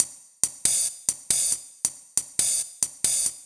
Razor Hats 138bpm.wav